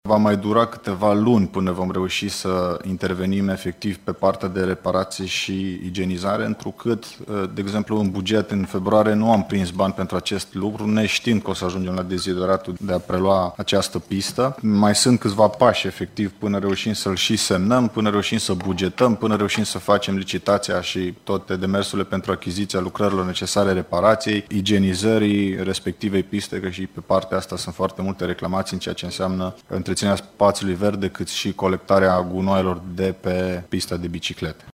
Deși administrația județeană a preluat pista în administrare, săptămâna trecută, de la Apele Banat, deocamdată luna aceasta nu sunt prevăzuți bani în buget pentru aceasta, spune vicepreședintele Consiliului Județean Timiș, Alexandru Proteasa: